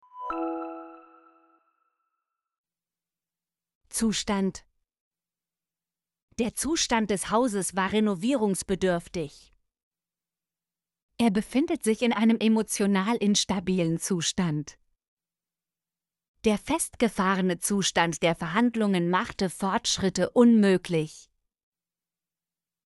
zustand - Example Sentences & Pronunciation, German Frequency List